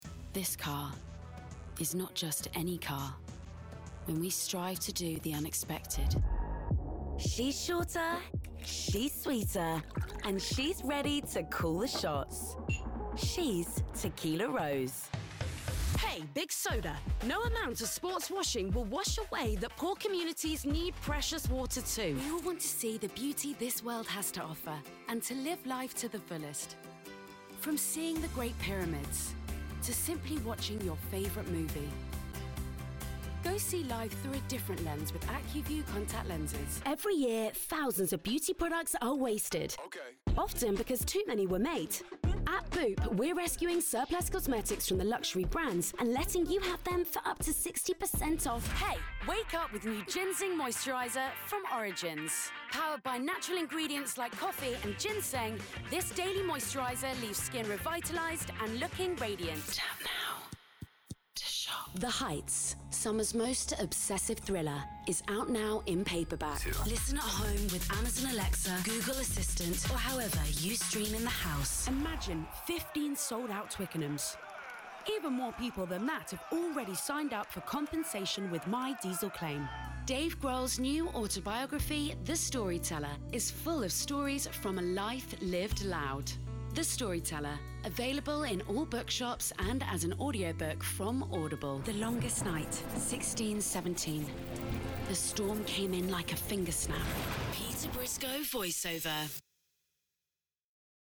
Female
British English (Native)
Approachable, Assured, Authoritative, Bright, Character, Confident, Conversational, Cool, Corporate, Deep, Energetic, Engaging, Friendly, Gravitas, Natural, Posh, Reassuring, Smooth, Upbeat, Warm, Young, Cheeky, Sarcastic, Witty
2025 Commercial Reel Short .mp3
Microphone: Rode NT2-A, Shure SM7B, Shure SM58